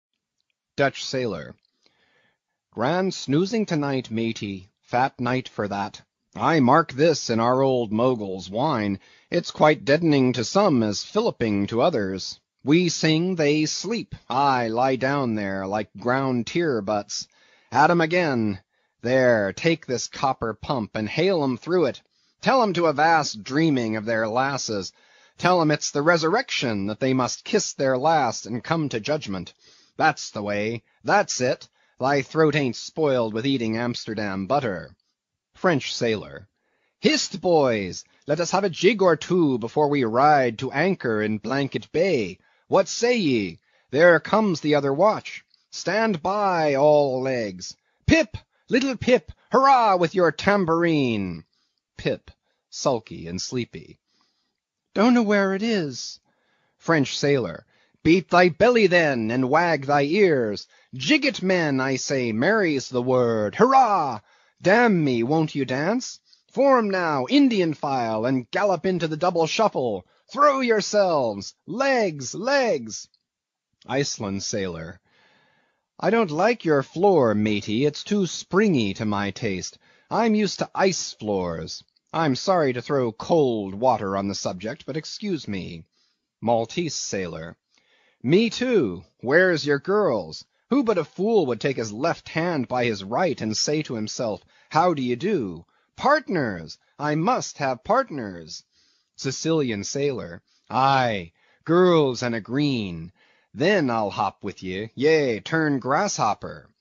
英语听书《白鲸记》第428期 听力文件下载—在线英语听力室